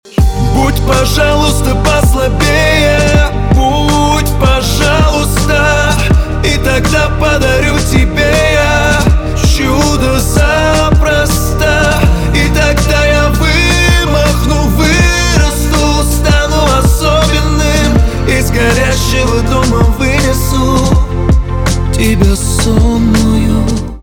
поп
чувственные , битовые , грустные